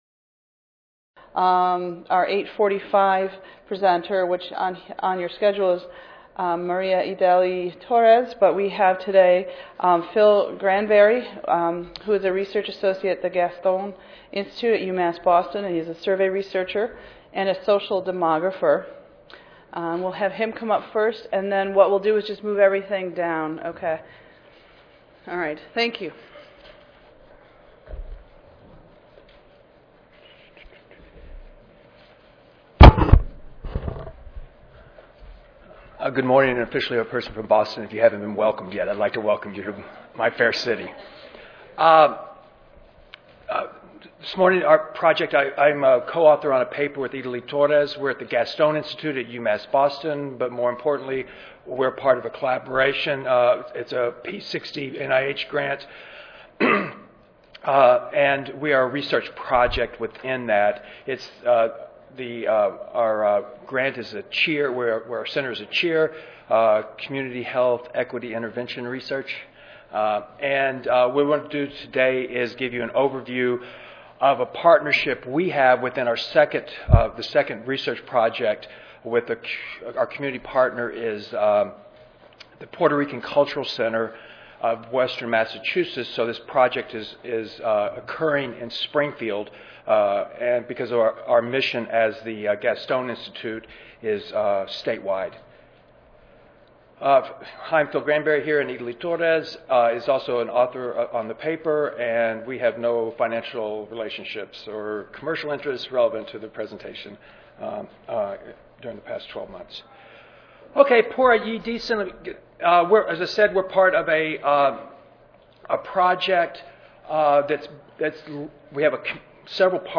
The panelists in this session will discuss and describe the strategies, benefits, opportunities and challenges that are involved with developing and sustaining academic community partnerships.